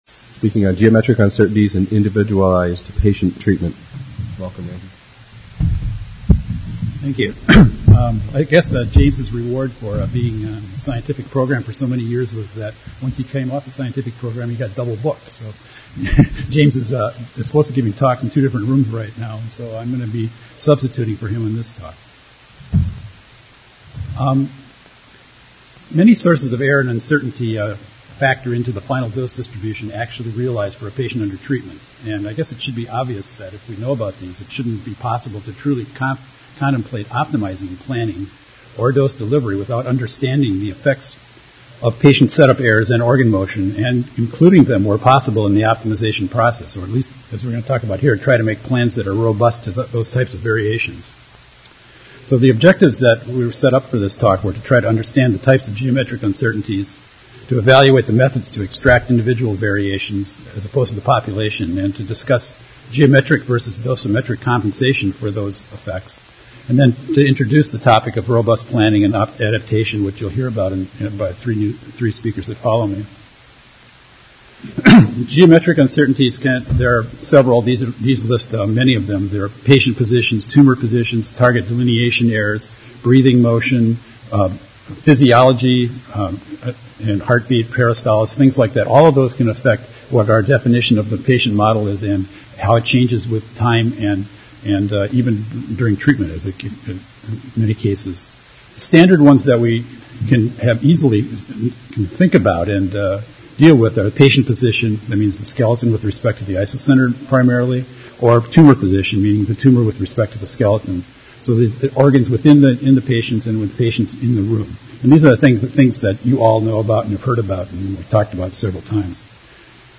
49th AAPM Annual Meeting - Session: Robustness of IMRT Treatments